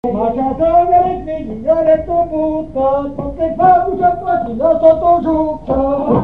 Chants brefs - A applaudir
chanteur(s), chant, chanson, chansonnette
circonstance : bachique
Pièce musicale inédite